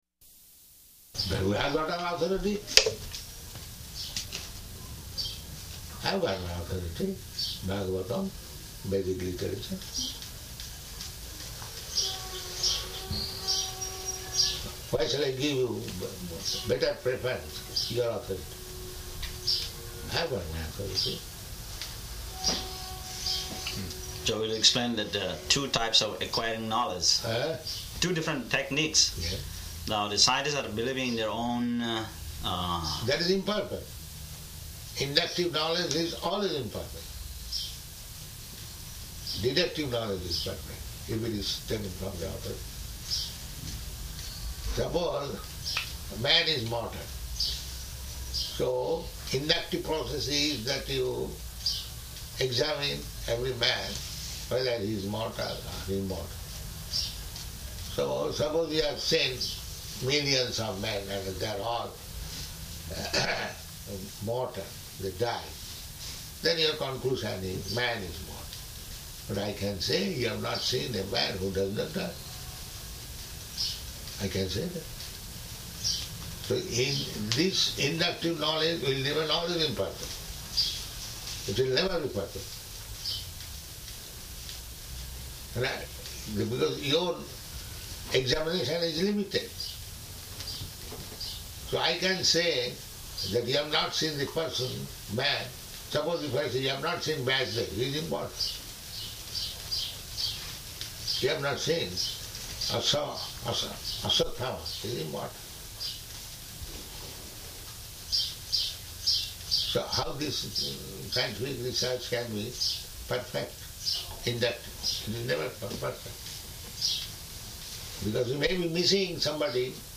Room Conversation